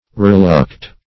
Reluct \Re*luct"\ (r?-l?kt"), v. i. [L. reluctari, p. p.